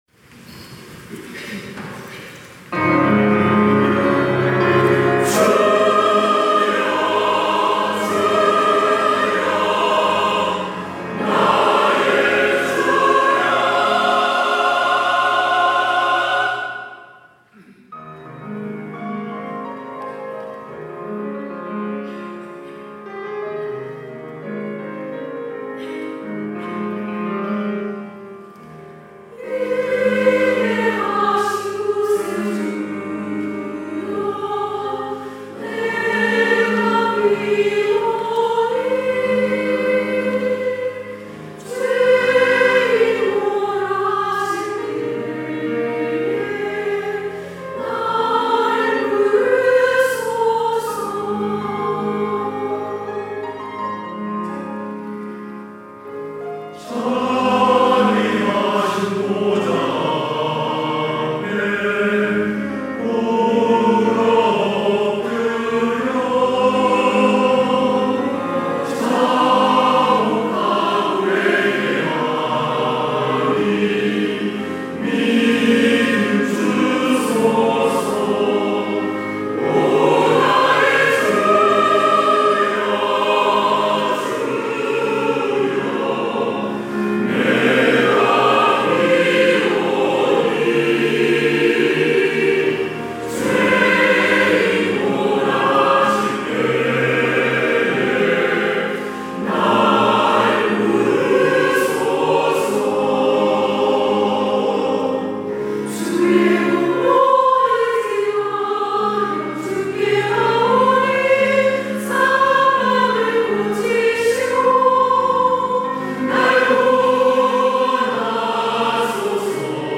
할렐루야(주일2부) - 인애하신 구세주여
찬양대